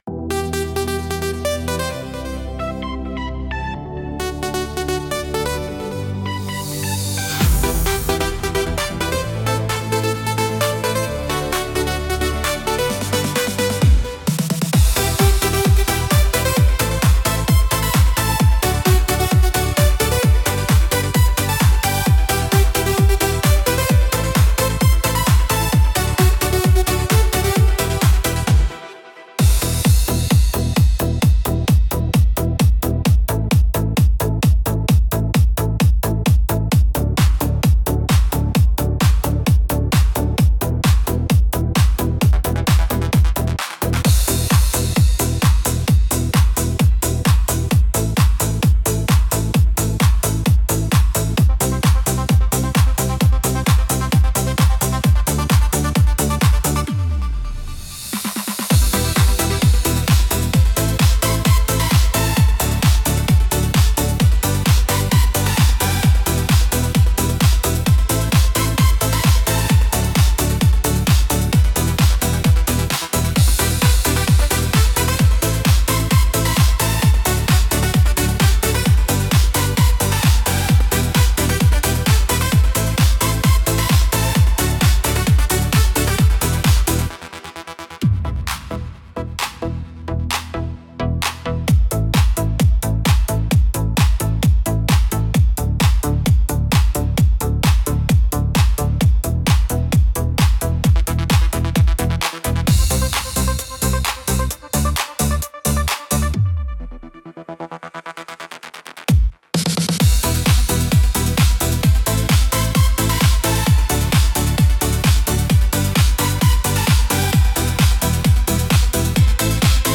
Instrumental - Hypercolor Sunrise 2.20